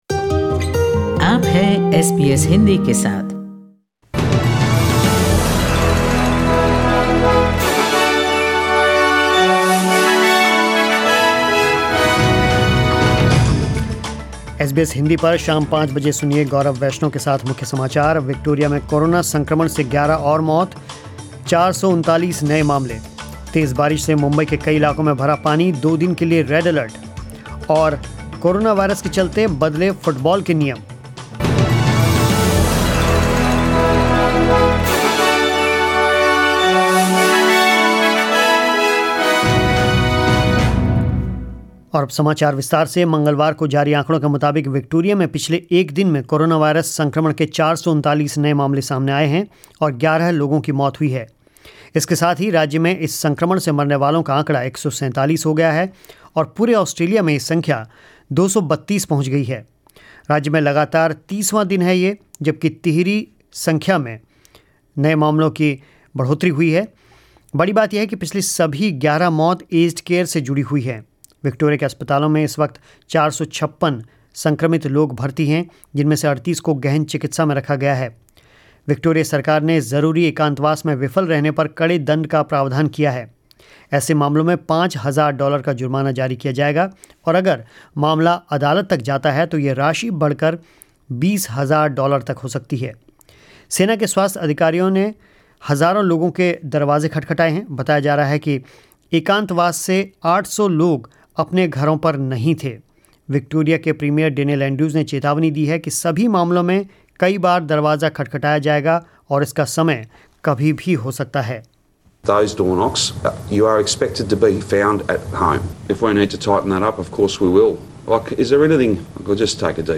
News in Hindi 04 August 2020